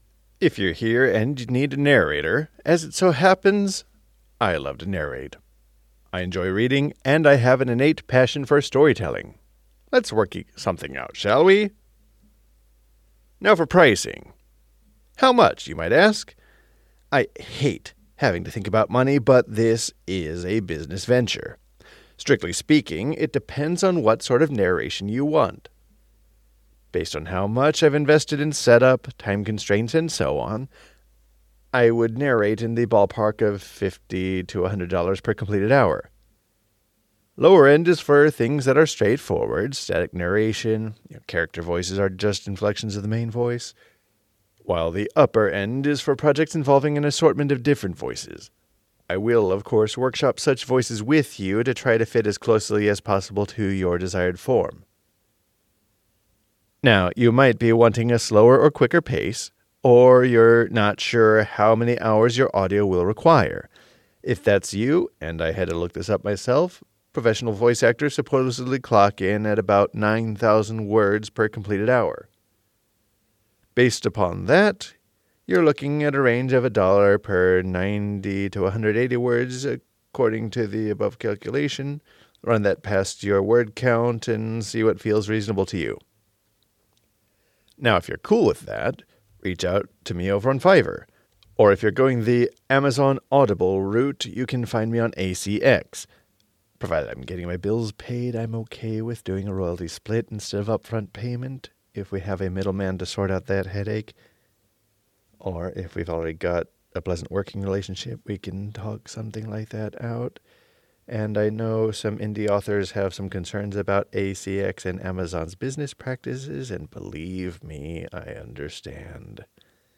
Voiceover Information
Your concern is more likely that I am using my real voice and not an AI.
Voiceover.mp3